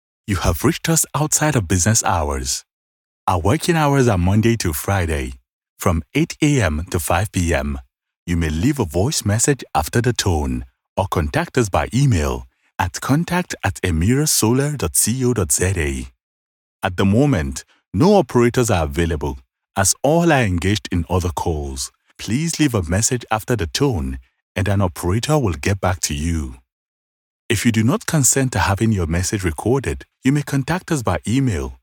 Natürlich, Zuverlässig, Warm, Kommerziell, Vielseitig
Telefonie
He has an authentic, articulate and clear voice which resonates with audiences across the globe.